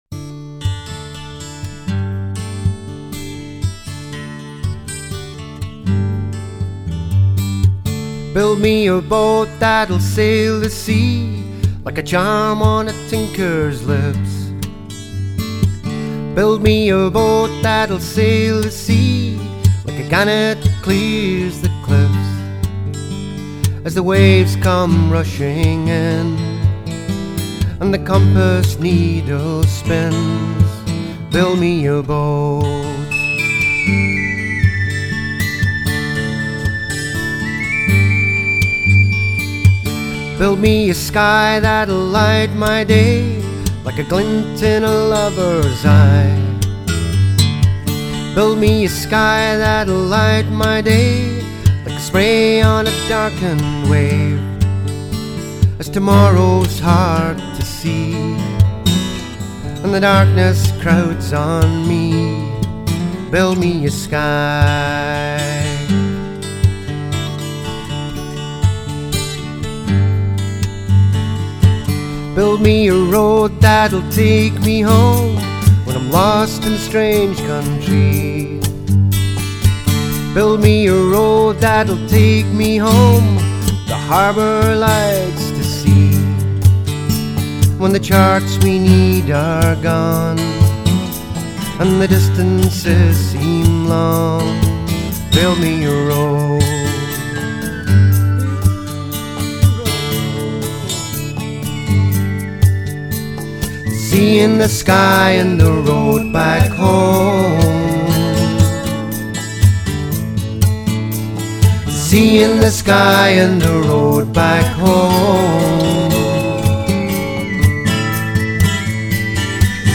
contemporary Scottish folk songs